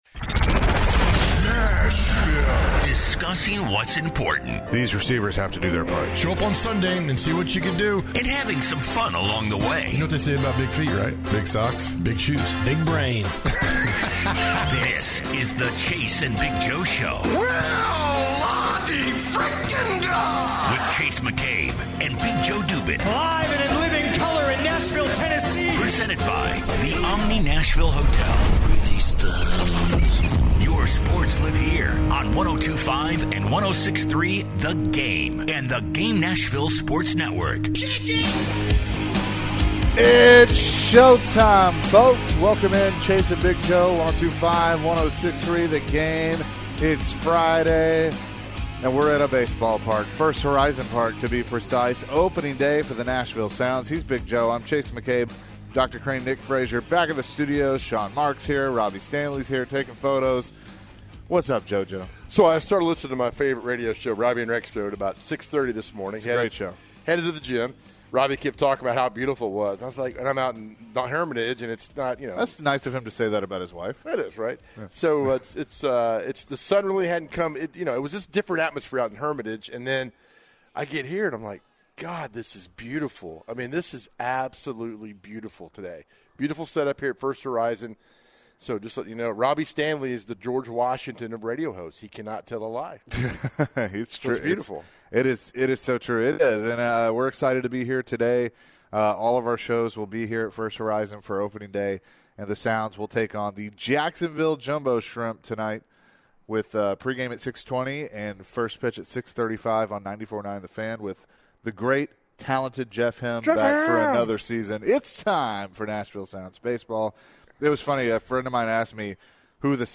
the guys were live from First Horizon Park for the Nashville Sounds Opening Day game this evening vs the Jacksonville Jumbo Shrimp.